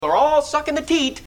Tags: Ryan Seacrest pissed off Ryan Seacrest rant Ryan Seacrest audio clip Ryan Seacrest Knocked Up Ryan Seacrest